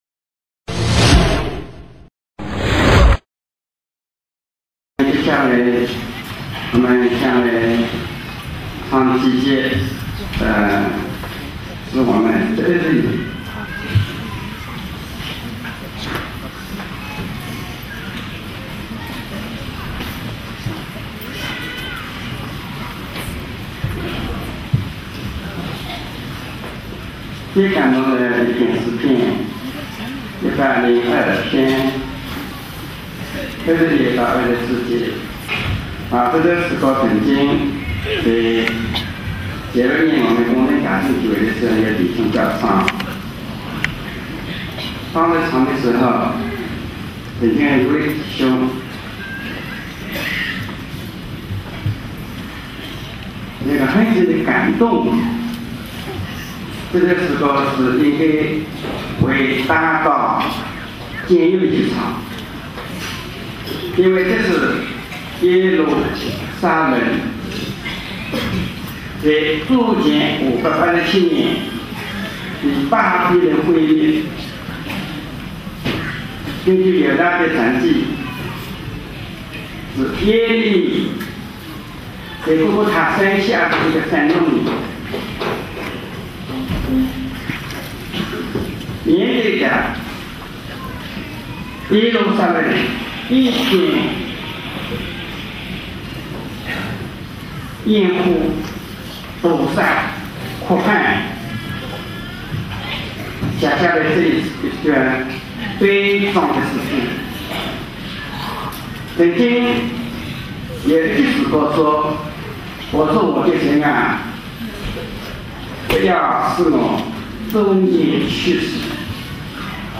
特会信息